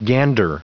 Prononciation du mot gander en anglais (fichier audio)
Prononciation du mot : gander